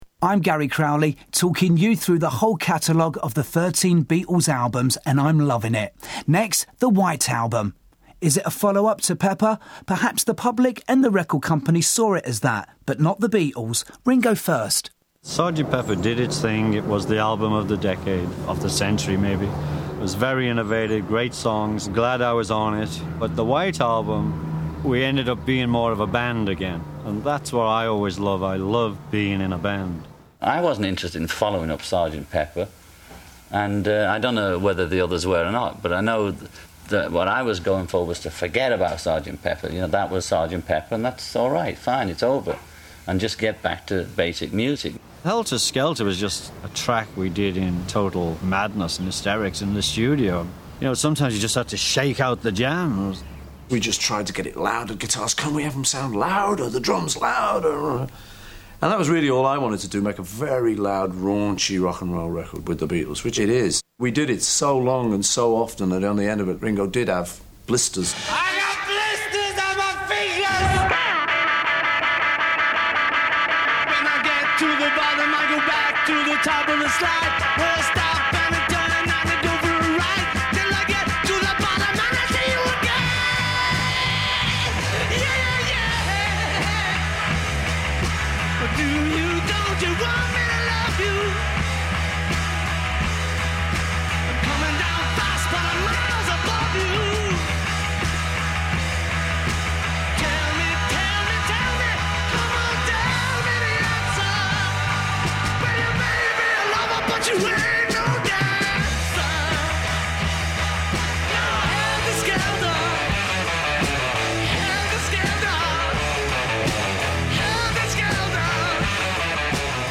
One very interesting thing is that some of the music tracks are preceded by original studio banter by the band that I can only presume comes from the The Beatles Rock Band game, also released on 09.09.09.  I don’t have the game, but I’ve read that they use previously unreleased studio chat and out-takes extensively to make the experience of playing it more realistic.